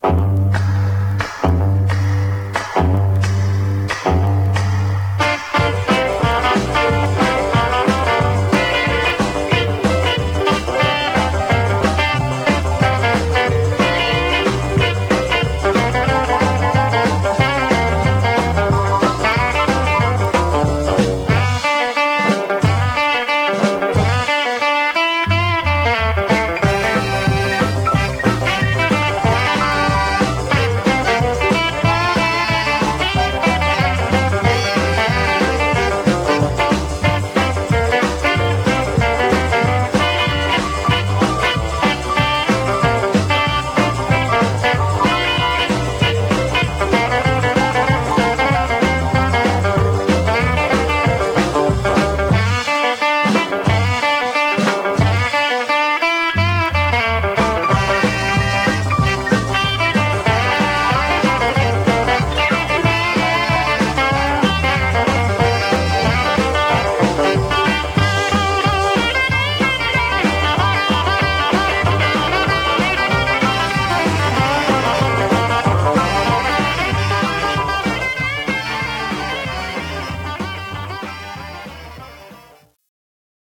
Mono
Rockabilly